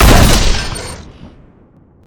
shoot3.wav